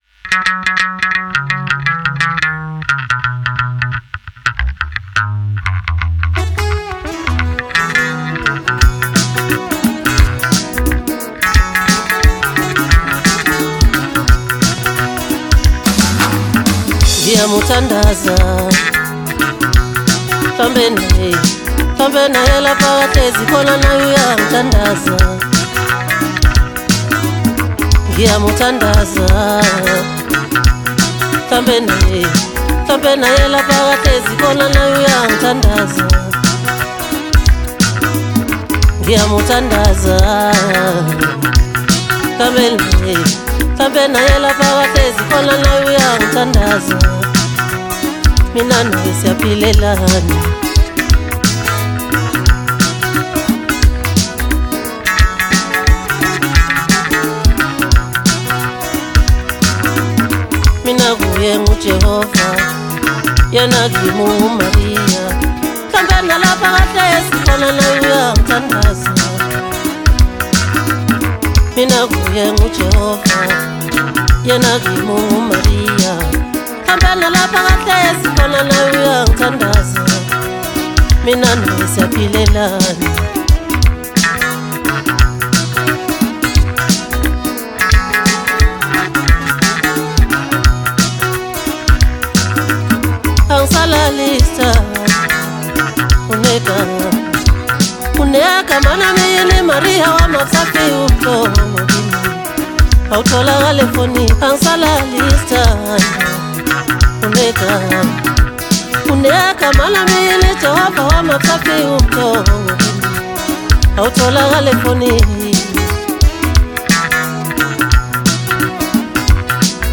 Home » Maskandi Music » Maskandi